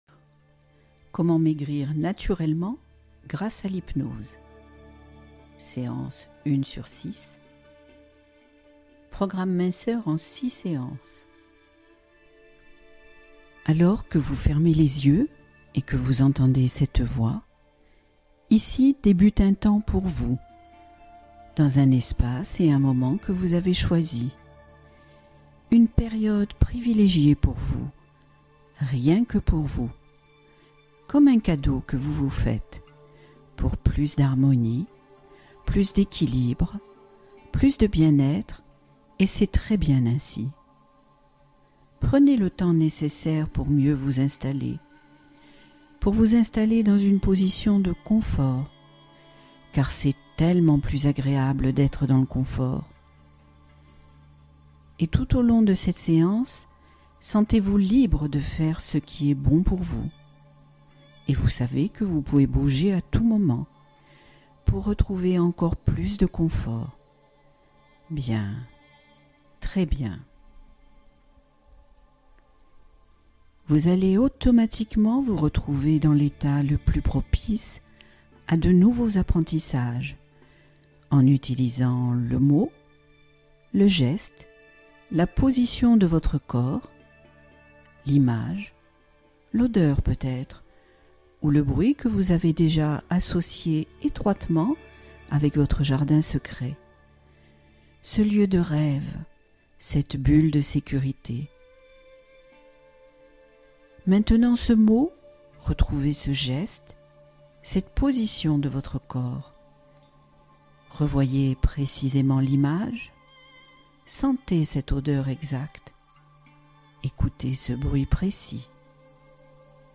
Explorer l’intuition — Hypnose douce en plusieurs séances